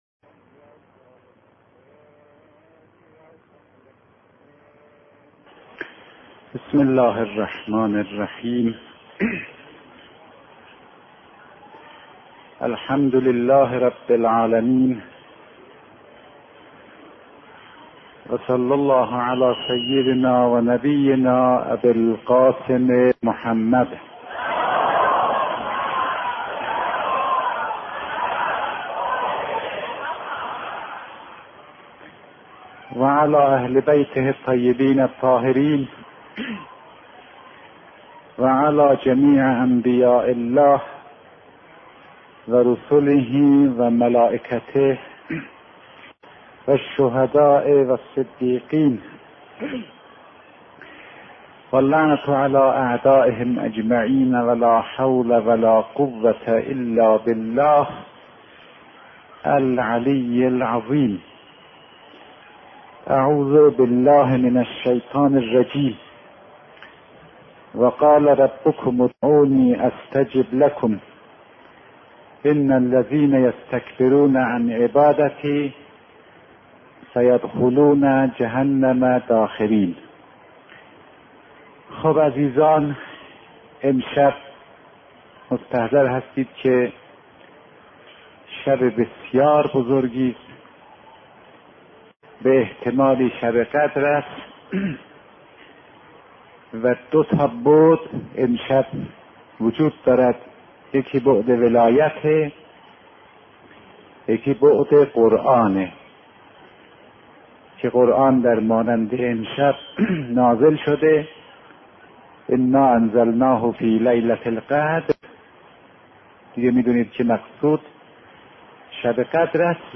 بیانات عارف بزرگوار حضرت آیت الله فاطمی نیا، پیرامون مباحث اخلاق در قرآن با عنوان «نزول قرآن» / مدت زمان : 61 دقیقه
این خطابه بر محور شب قدر می‌چرخد، شبی که باور بر این است که قرآن در آن نازل شده و فرشتگان به زمین می‌آیند و با امام زمان (عجل‌الله‌تعالی‌فرجه) ملاقات می‌کنند و مقدرات را به او عرضه می‌دارند.